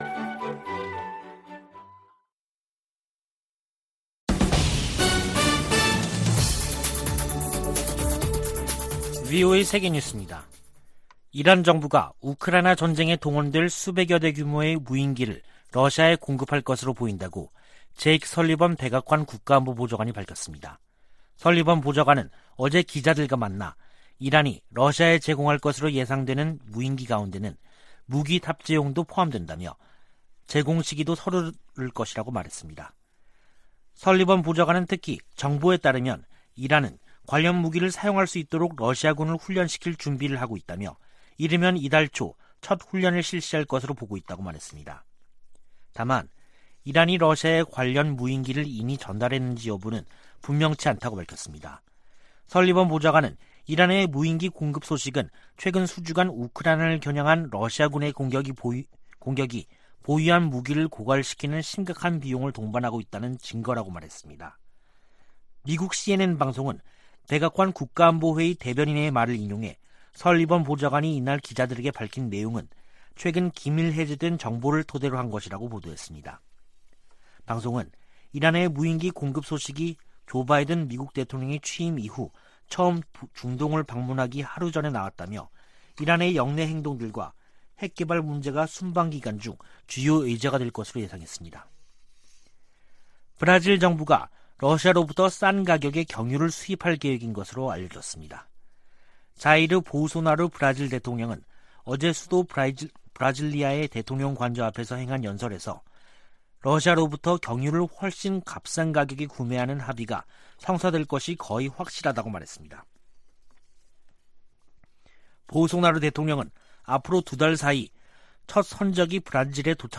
VOA 한국어 간판 뉴스 프로그램 '뉴스 투데이', 2022년 7월 12일 3부 방송입니다. 올 후반기 미-한 연합지휘소훈련이 다음달 22일부터 9월1일까지 실시될 전망입니다. 북한 군이 4주 만에 방사포 발사를 재개한 것은 한국을 실제로 타격하겠다는 의지와 능력을 과시한 것이라고 전문가들은 진단했습니다. 마크 에스퍼 전 미 국방장관은 주한미군에 5세대 F-35 스텔스기를 배치해야 한다고 주장했습니다.